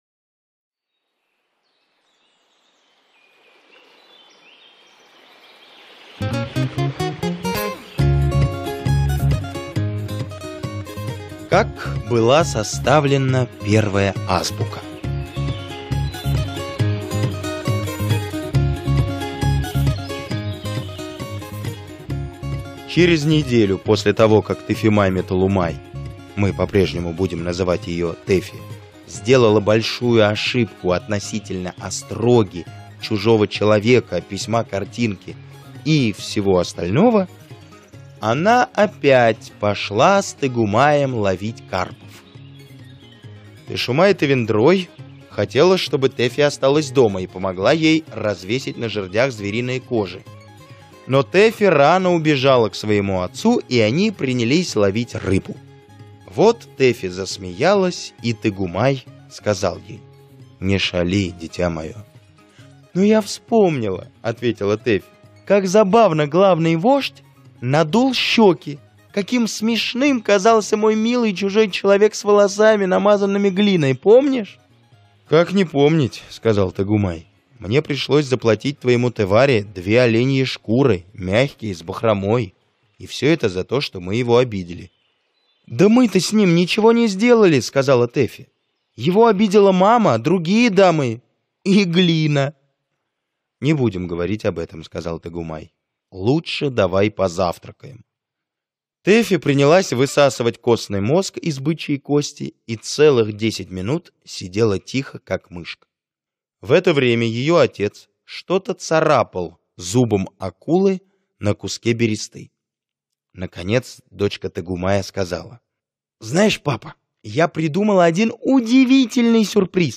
Как была составлена первая азбука - аудиосказка Киплинга - слушать